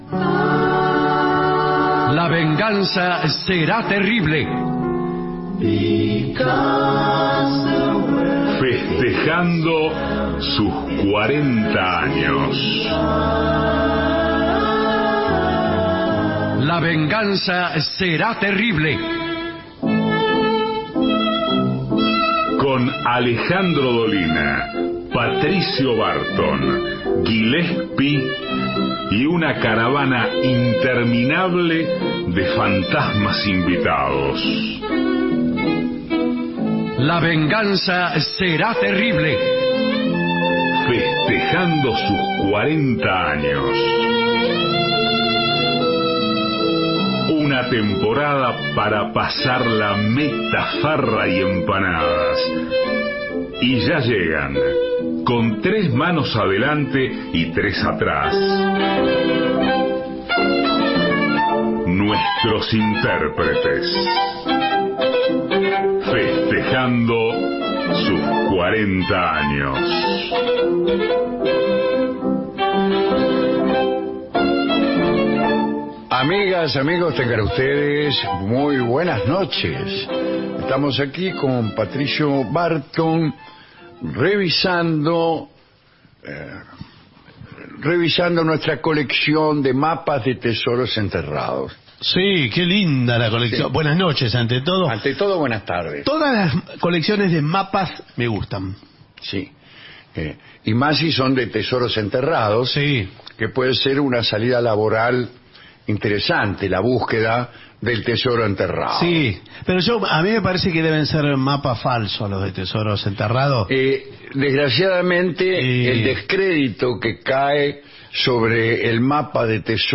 Estudios AM 750